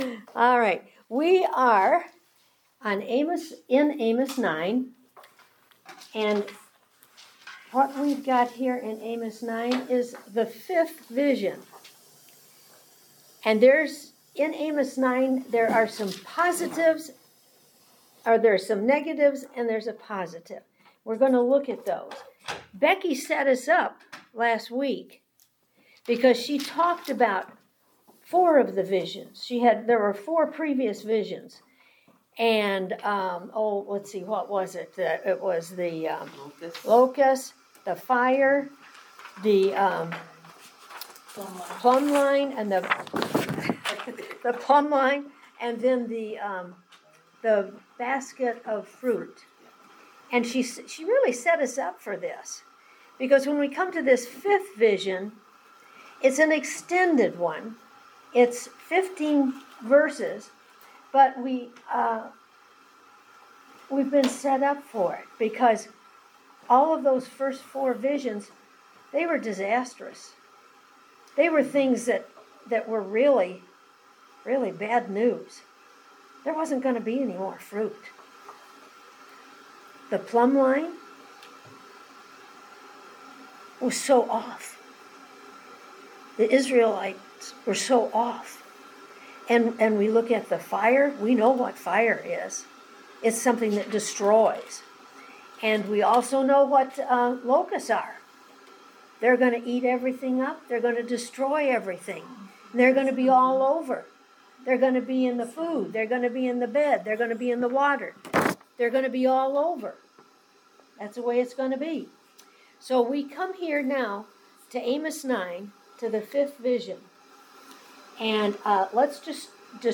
Service Type: Women's Bible Study